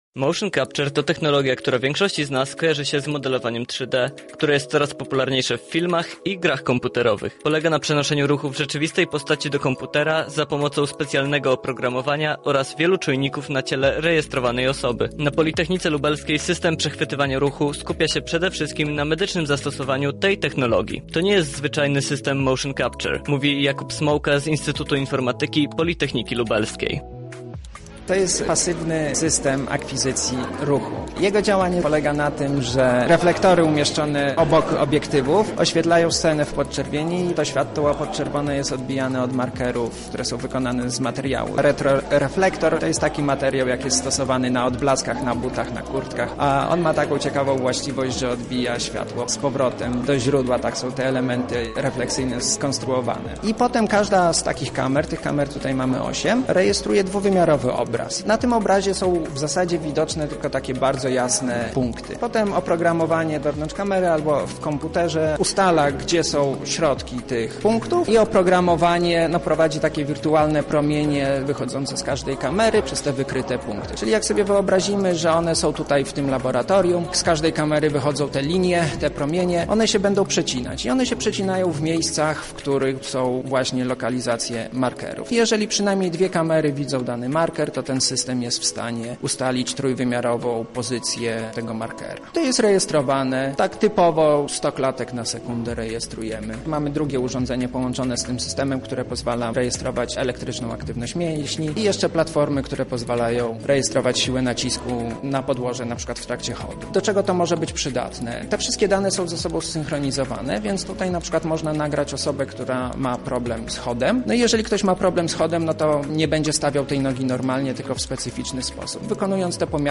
Więcej na ten temat dowiedział się nasz reporter: